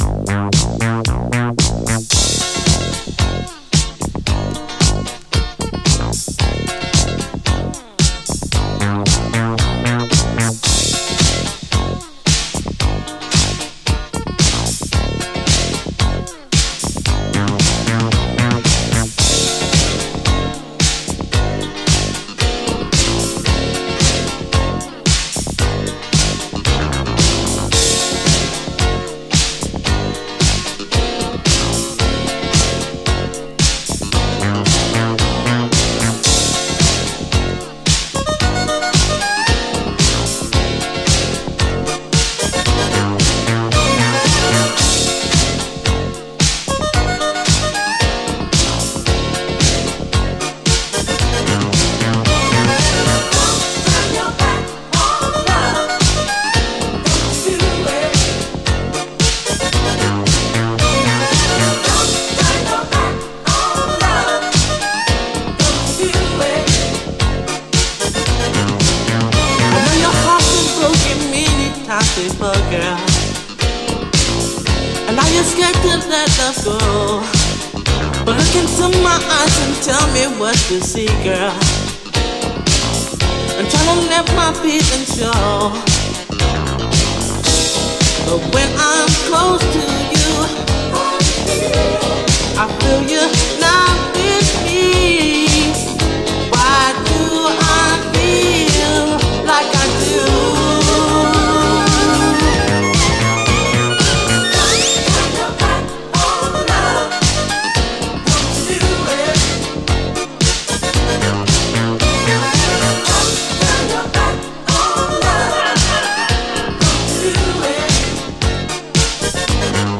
SOUL, 70's～ SOUL, DISCO
カナダ産80’Sキャッチー・シンセ・ディスコ・クラシック！